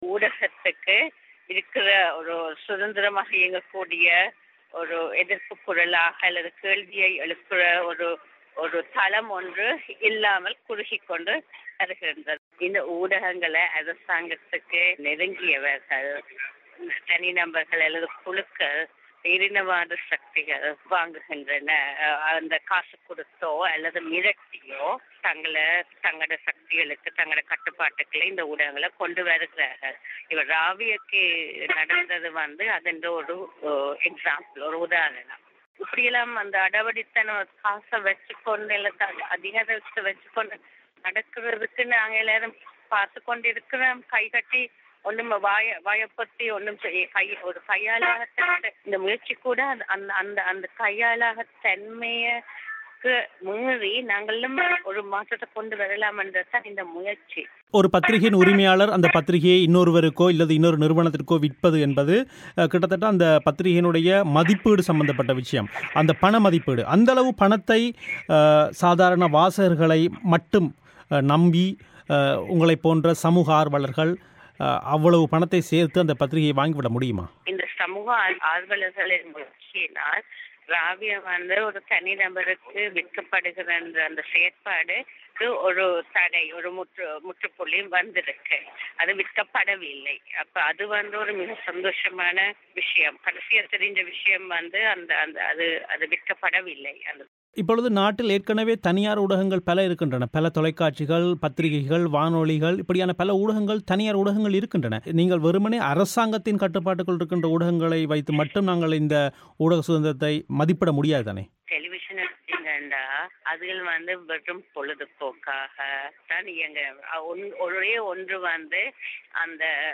செவ்வி - ராவய சிங்கள பத்திரிகையை தனியாருக்கு விற்பதை எதிர்க்கும் சமூக ஆர்வலர்களின் கருத்து.